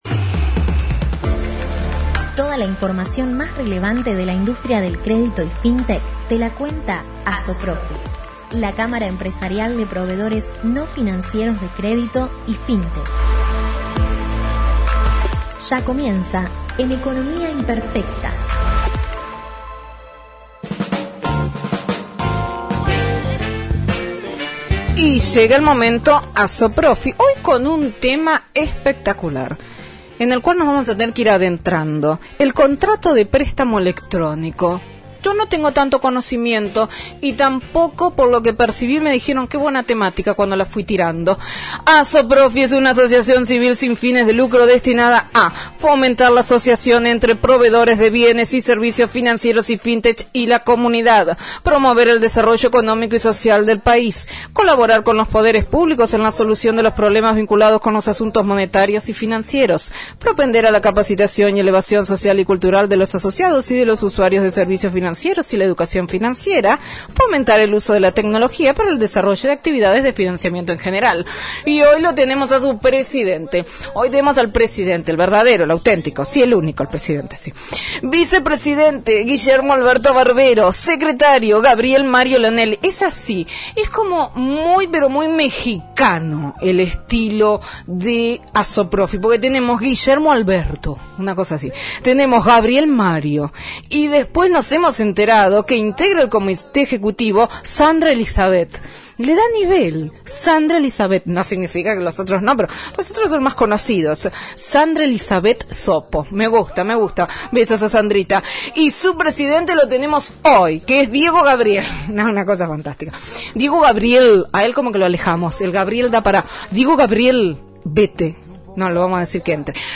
Para volver a escuchar la Columna Radial ingresando aquí: